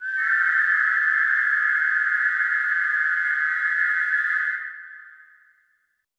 WHIS CLS  -R.wav